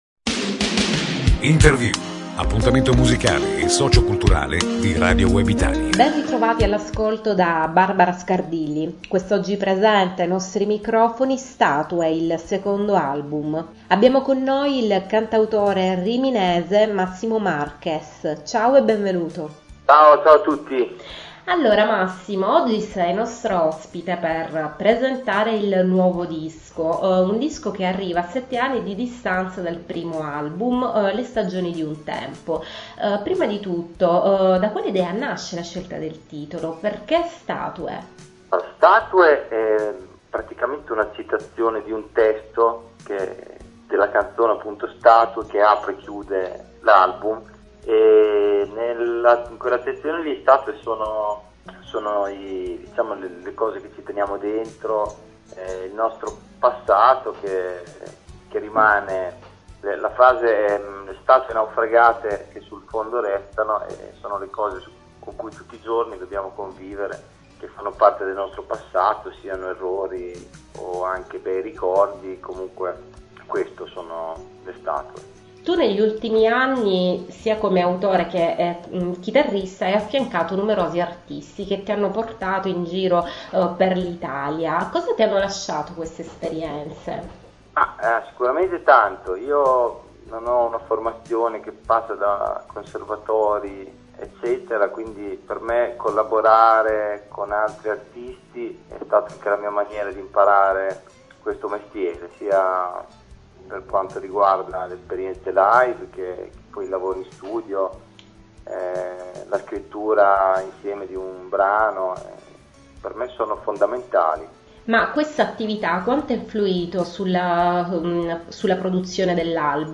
In News